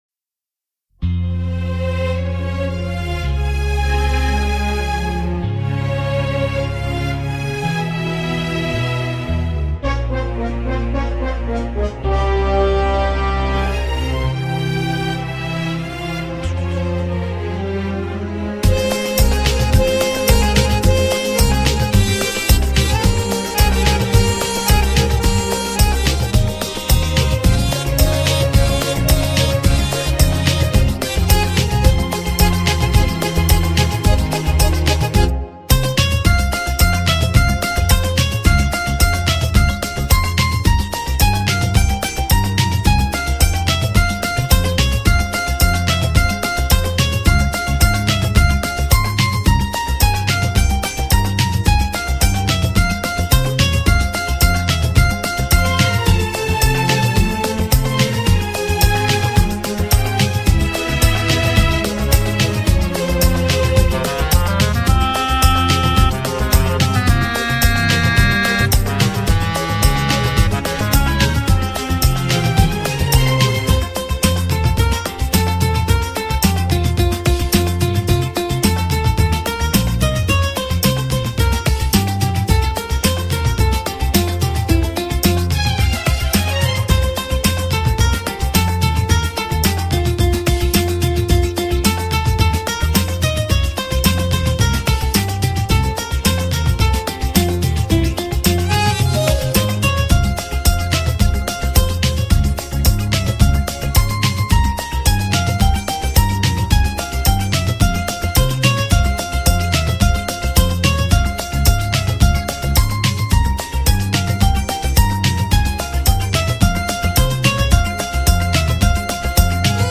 HomeMp3 Audio Songs > Instrumental Songs > Old Bollywood